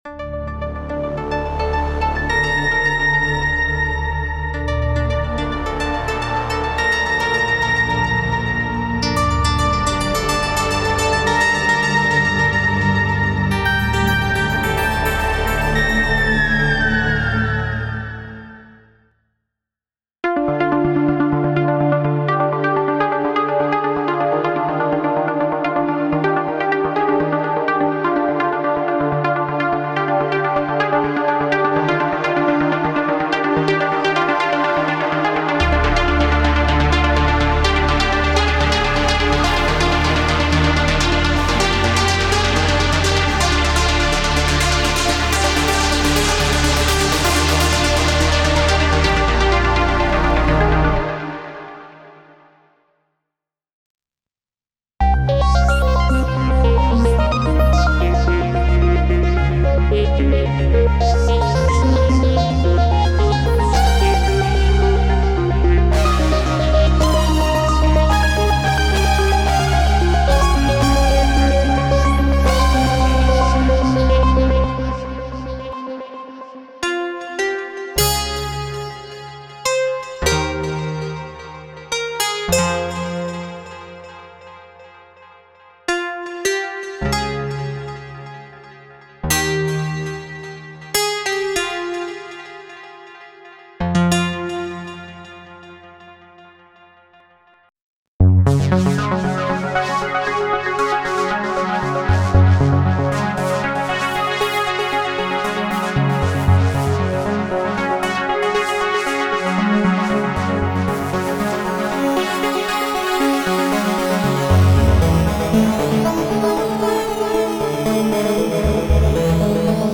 Ambient Cinematic / FX Psy-Trance Trance
Genre: Ambient, Cinematic, Electronic